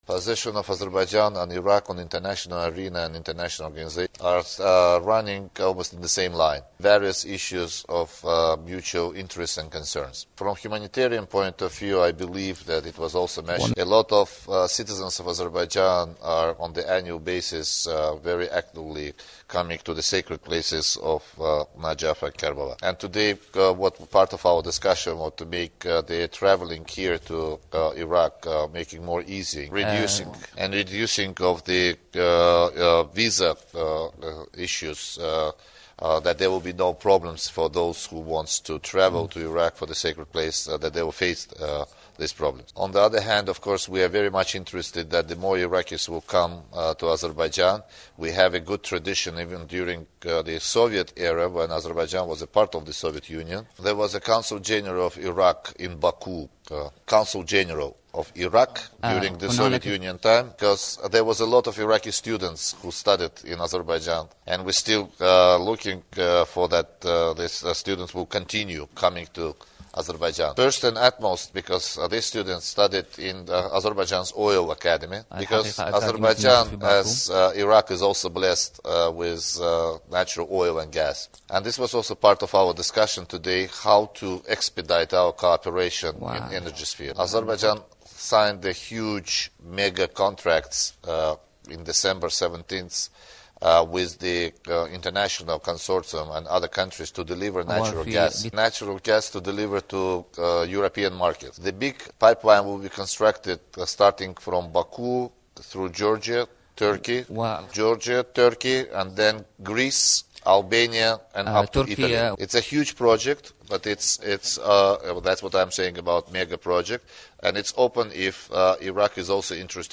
Elmar Mammadyarov's statement during the joint press-conference in Bagdad
Azeri FM Elmar Mammadyarov during joint press-conferance in Iraq